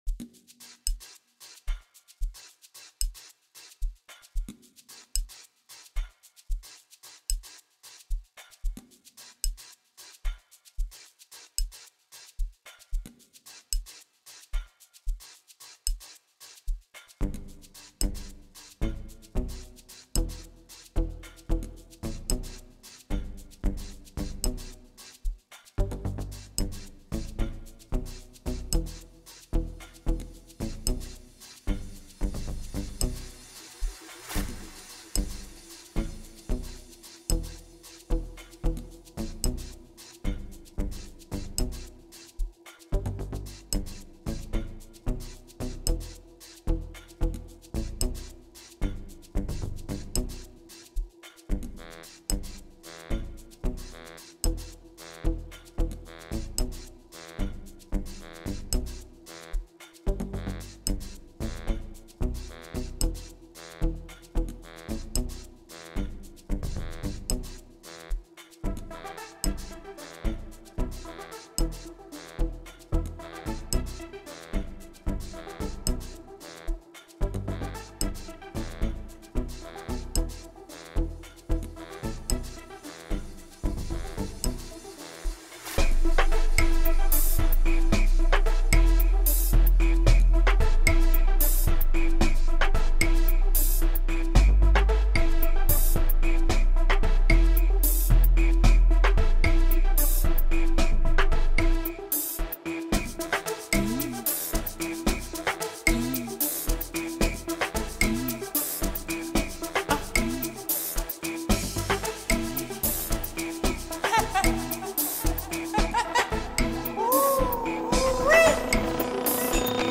With its catchy melody and funky vibe
a world of funky grooves and good vibes